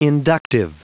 Prononciation du mot inductive en anglais (fichier audio)
Prononciation du mot : inductive